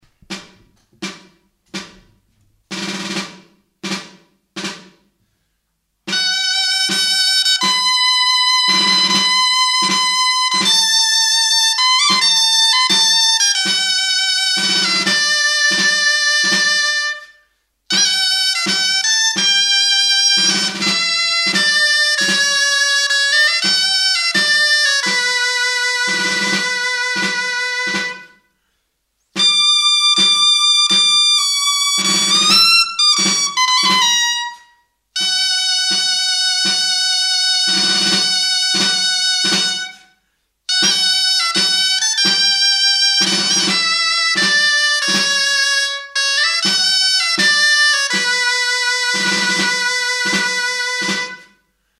Instrumentos de músicaDOLÇAINA
Aerófonos -> Lengüetas -> Doble (oboe)
HM udazkeneko Kontzertua, Oiartzun, 2002-10-19.
DOLÇAINA
Mihi bikoitzeko soinu-tresna da.